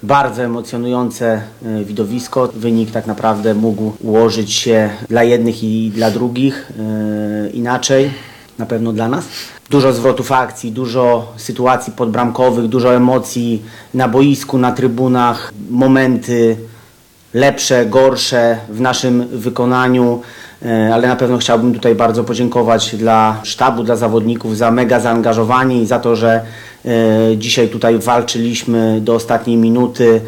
Pomeczowy komentarz